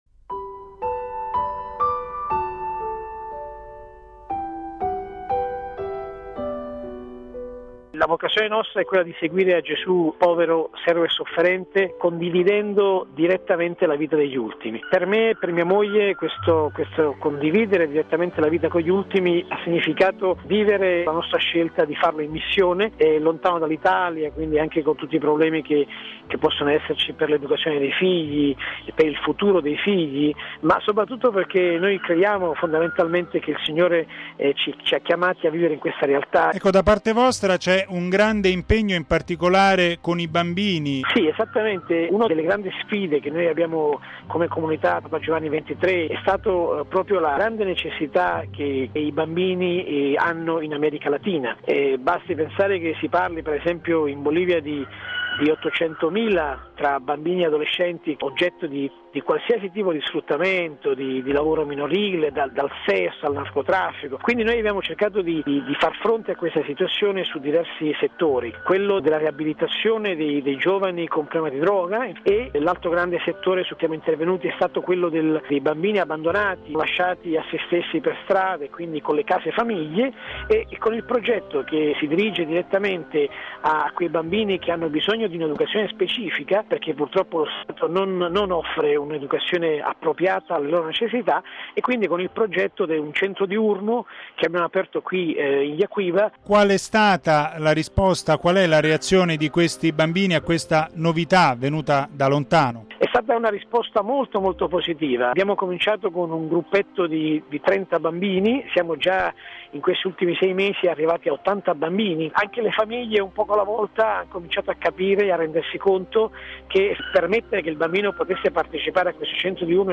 Raggiunto telefonicamente in Bolivia